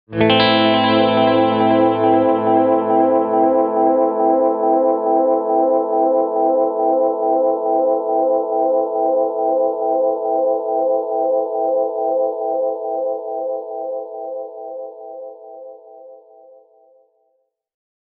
Tältä Flashback X4:n efektityypit kuulostavat:
Tape
tape.mp3